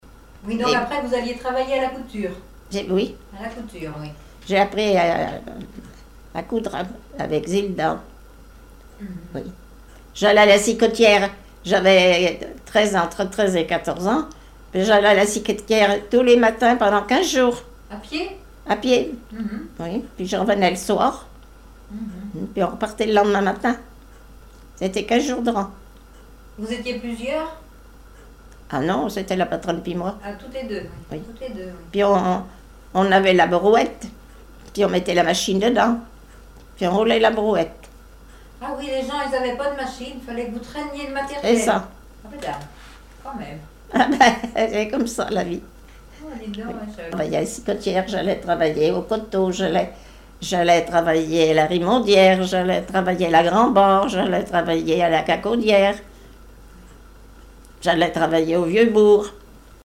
chansons et témoignages
Catégorie Témoignage